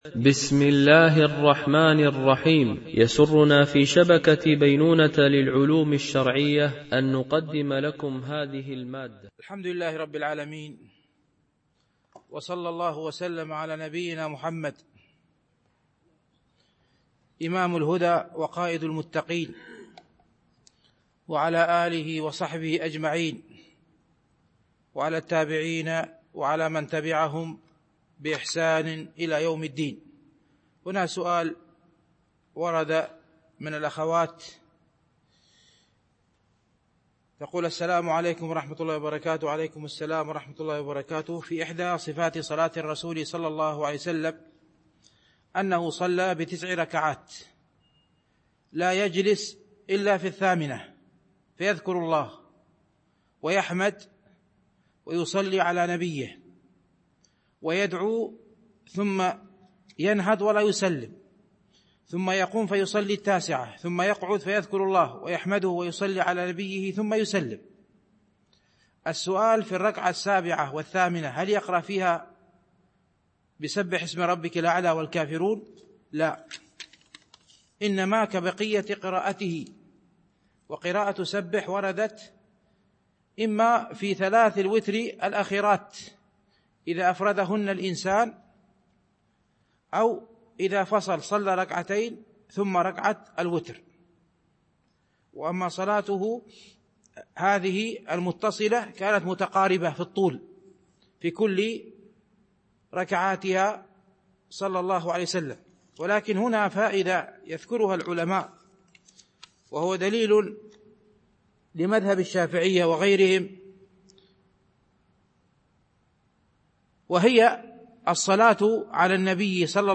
شرح رياض الصالحين – الدرس 305 ( الحديث 1182 - 1183 )